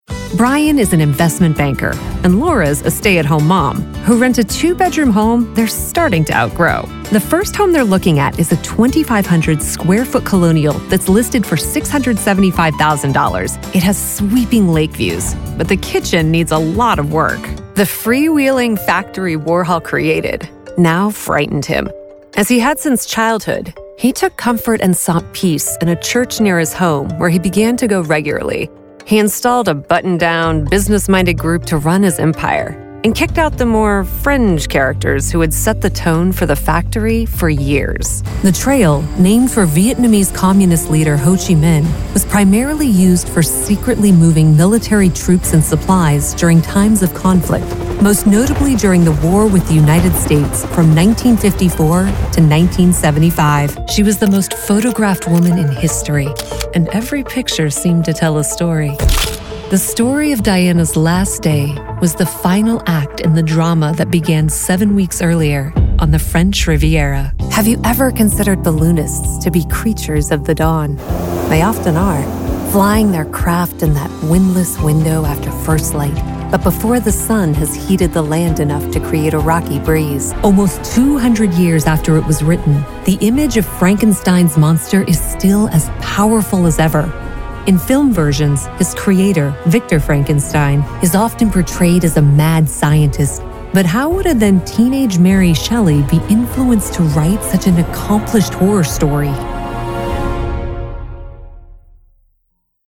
Broadcast Narration Demo